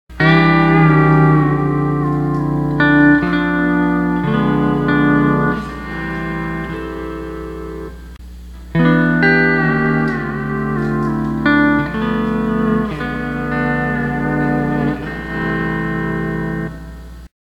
Steel Guitar Tab / Lessons
C6th 3rd String C to C# change - Example 7 Tab